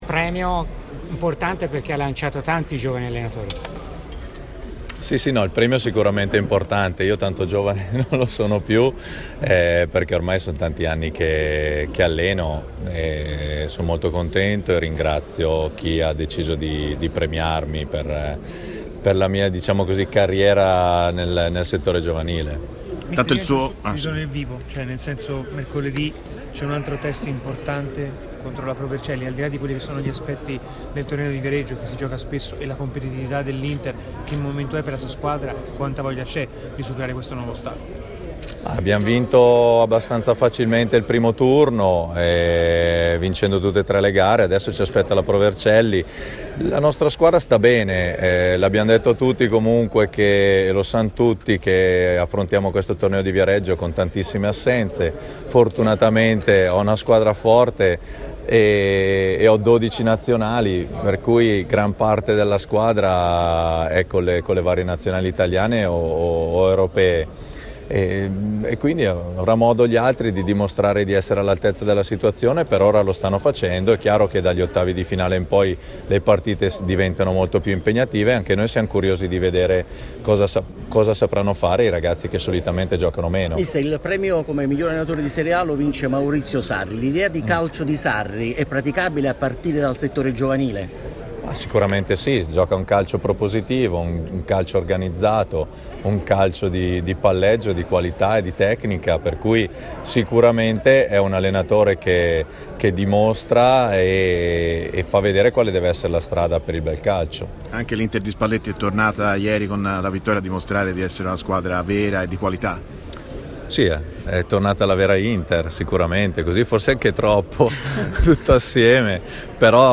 al Premio Maestrelli. © registrazione di TMW Radio